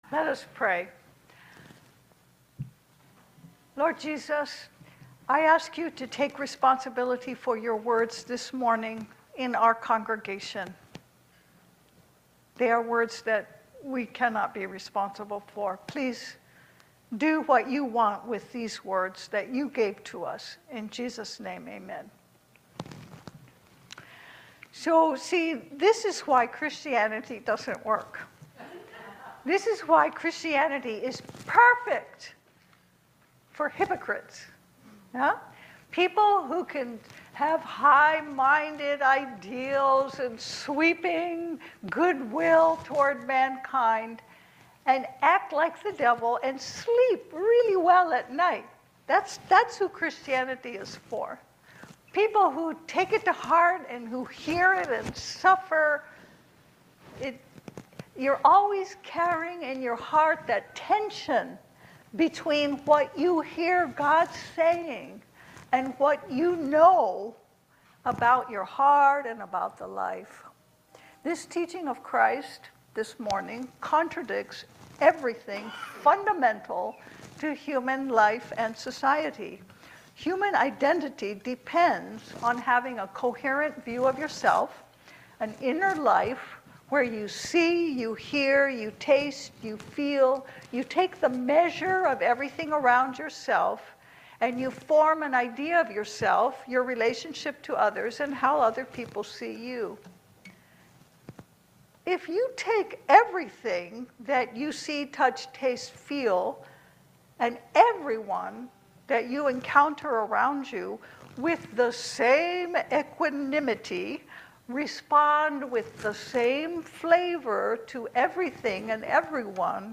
Luke 6:27-38 Service Type: Sunday Service Change of heart and life together are the seed of the kingdom to come.